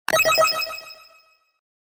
古き良きゲーム機の中で冒険しているような感覚を呼び覚ます。